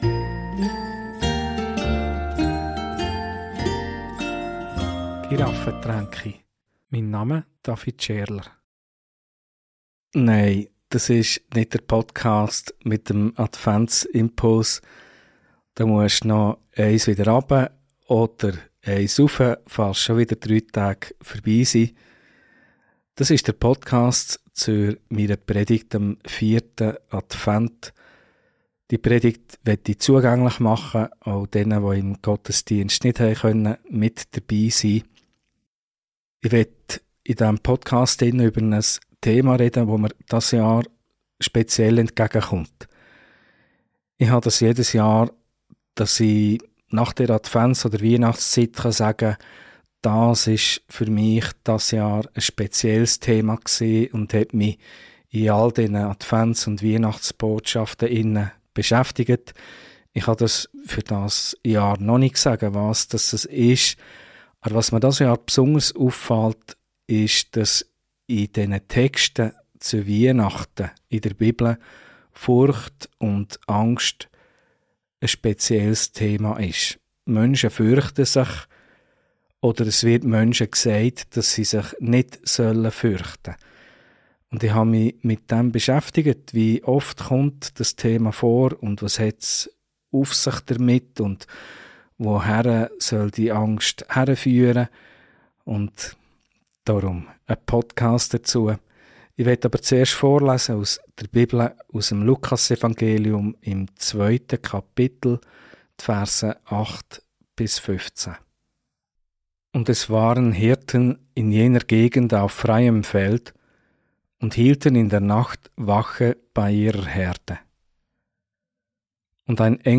Predigt - Von grosser Angst zu grosser Freude ~ Giraffentränke Podcast